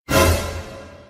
Suspenso Remixero